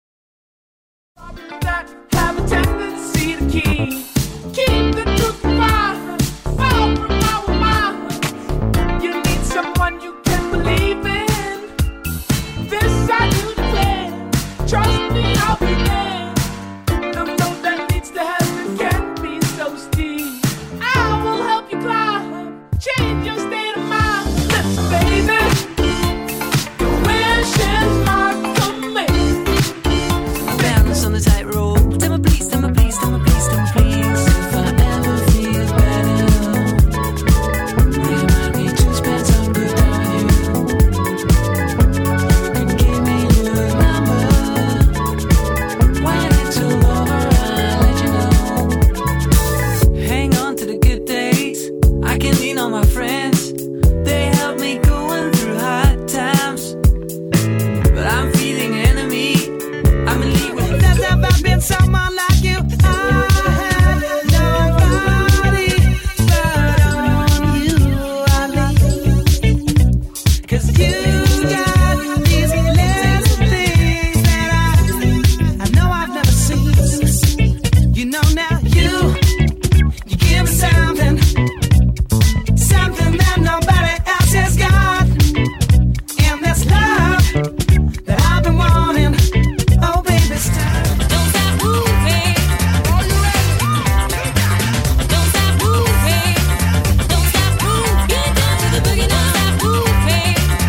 Funk Dance
Funk Dance Tunes for Dance Party